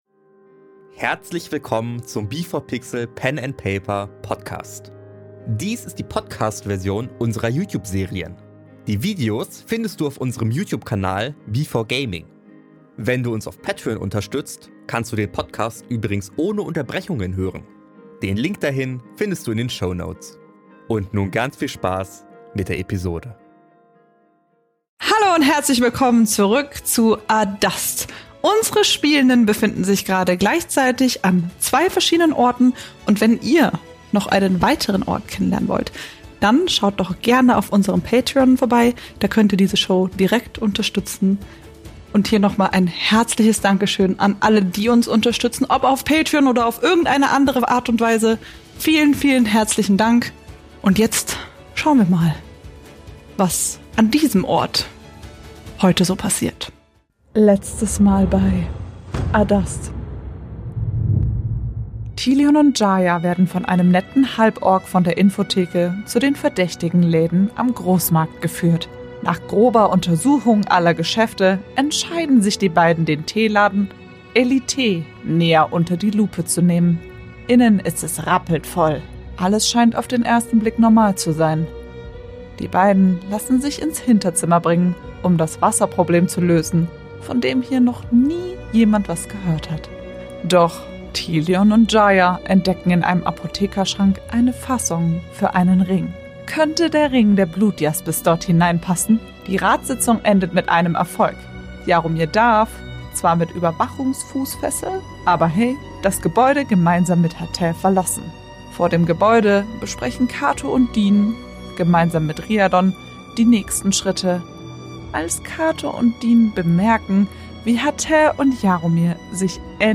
In dieser Serie entstehen aufgrund ihrer improvisatorischen Art immer wieder Situationen mit verschiedensten Themen und Inhalten, die in euch ungewollte Erinnerungen oder auch Gefühle hervorrufen können und generell schwerer zu verarbeiten sind.
Dies hier ist die Podcast-Version mit Unterbrechungen.